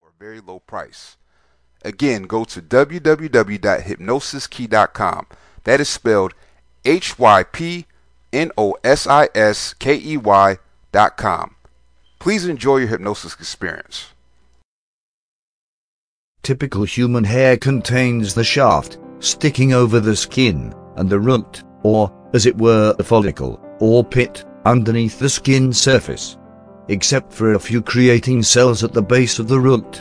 Hair Growth hypnosis helps you grow longer hair. Listen to this audio mp3, which is read by a hypnotherapist.
hairgrowthhypnosis.mp3